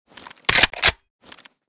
rpk_unjam.ogg